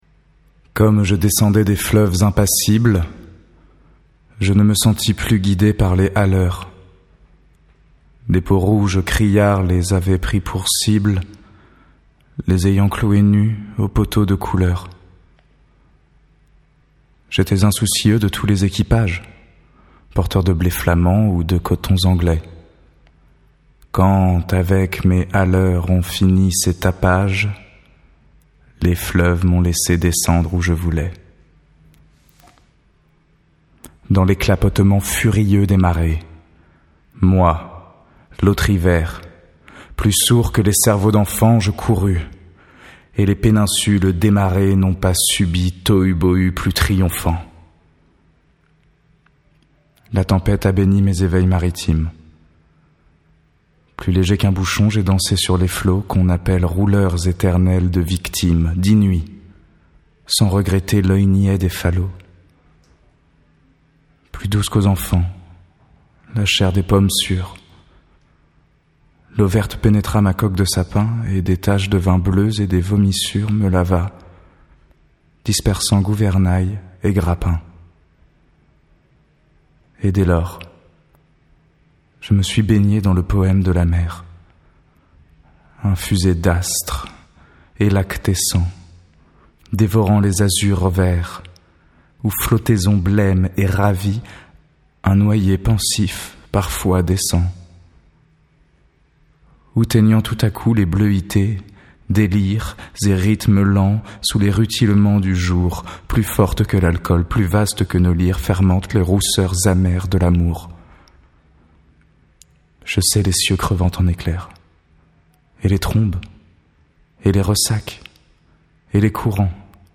Le Bateau Ivre - Arthur Rimbaud (lecture radiophonique)